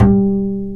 Index of /90_sSampleCDs/Roland - Rhythm Section/BS _Jazz Bass/BS _Ac.Fretless